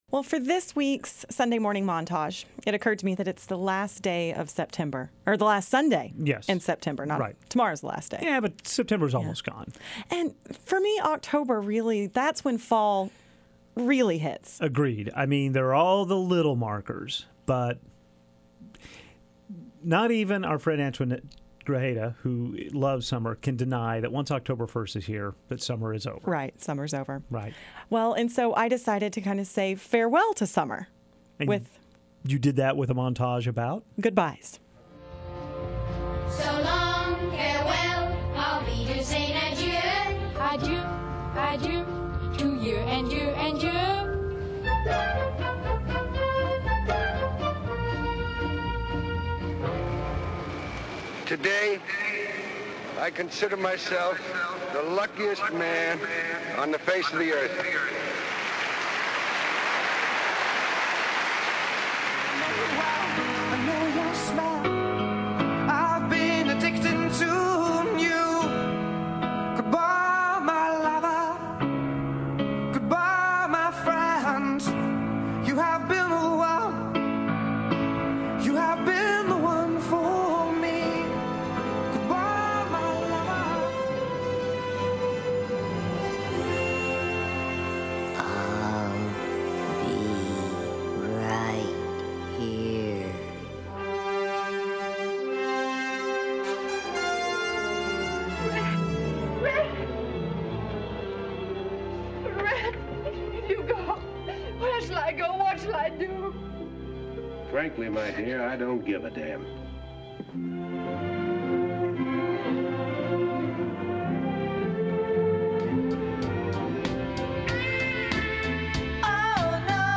With this morning's montage, we say several goodbyes; to Summer, to a famed Yankees pitcher, and to one of our longest-tenured colleagues at KUAF. Here are our 10 pop culture references we used to say goodbye.